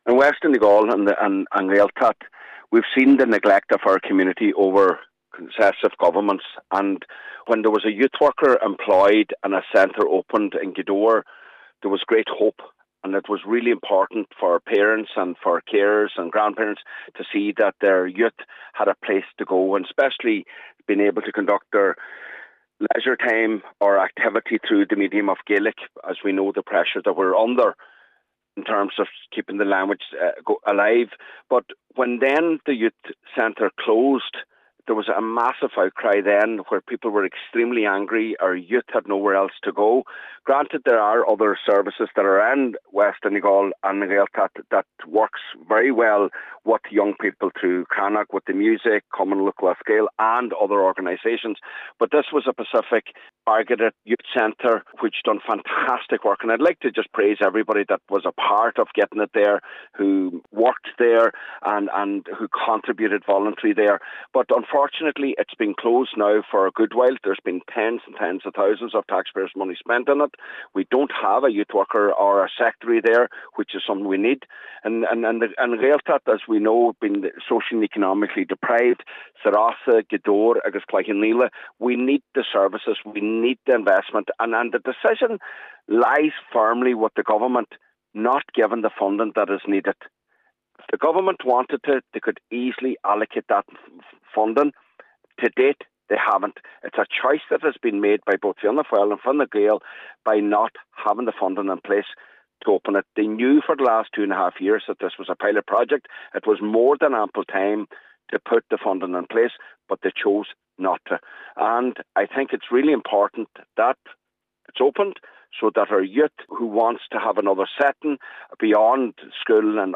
Councillor Micheal Choilm MacGiolla Easbuig says with thousands already invested in the centre, it’s vital money is found to continue the provision of the much-needed service in West Donegal: